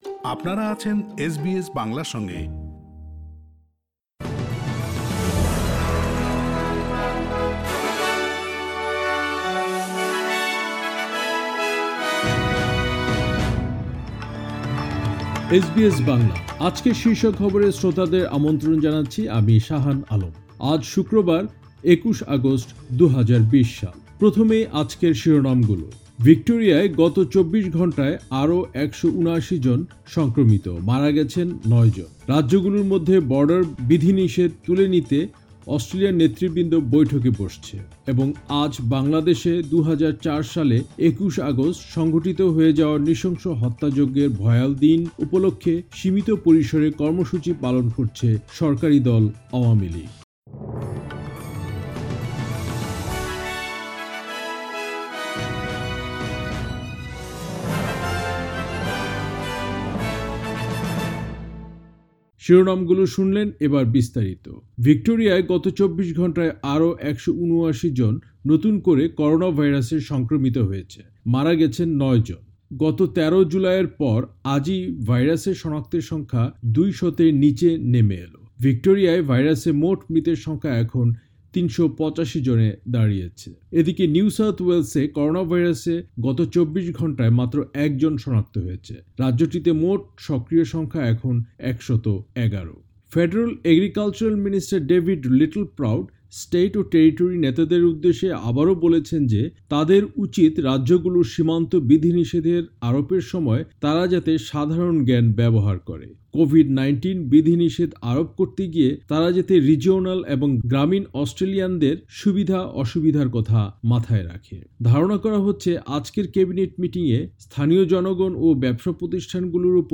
এসবিএস বাংলা শীর্ষ খবর, ২১ অগাস্ট, ২০২০